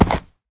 horse
jump.ogg